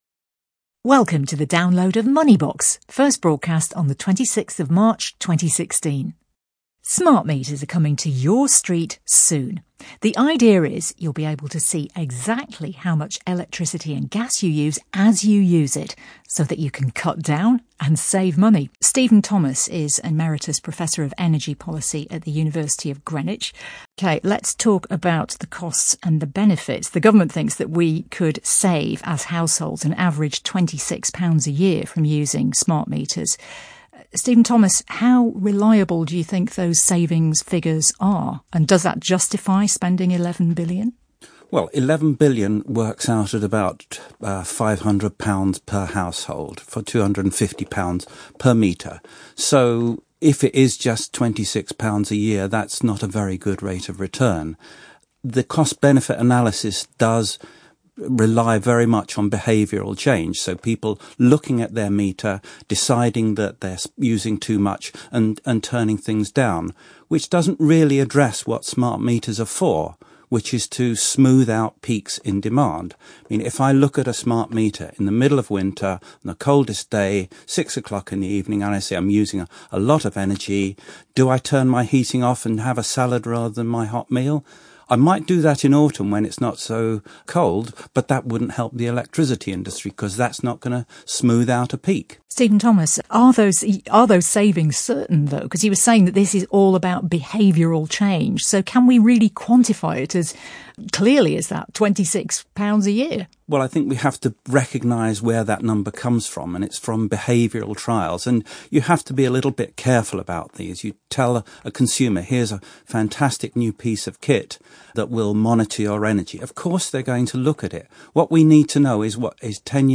An audio excerpt of the BBC radio program is provided below.